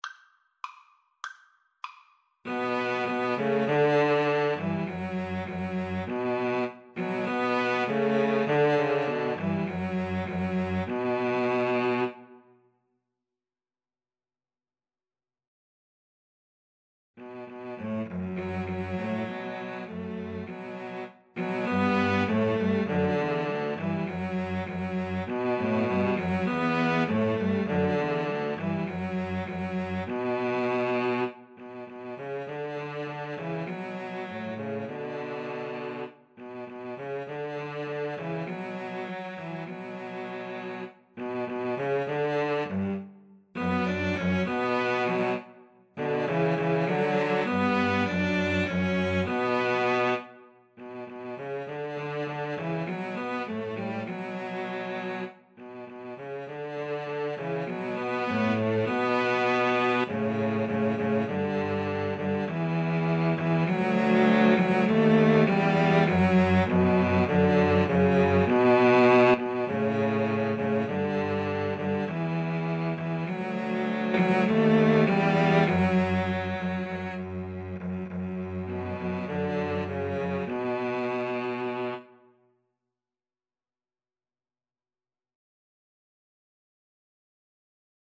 Free Sheet music for Cello Trio
Moderato
B minor (Sounding Pitch) (View more B minor Music for Cello Trio )